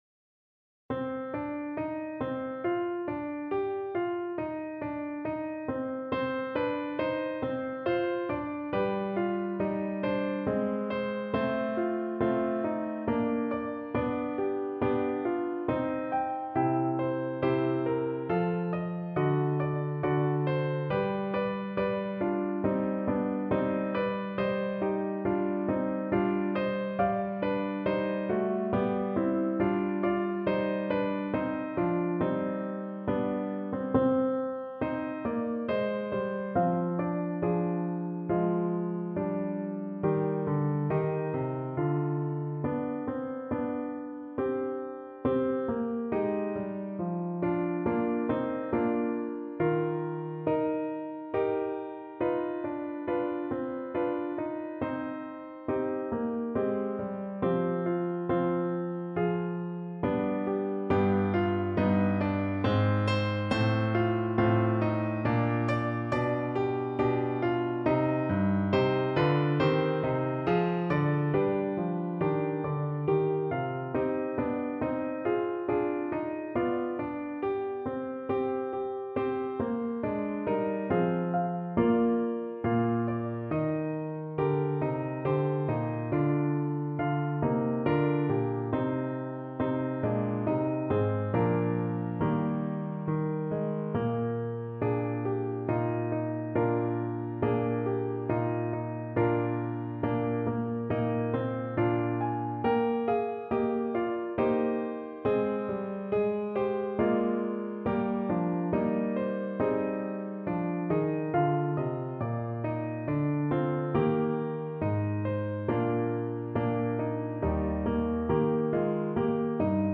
Alto Saxophone
3/4 (View more 3/4 Music)
G4-C6
C minor (Sounding Pitch) A minor (Alto Saxophone in Eb) (View more C minor Music for Saxophone )
= 69 Largo
Classical (View more Classical Saxophone Music)